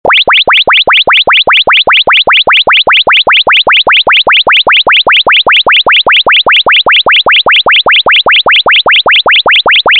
Categoría Alarmas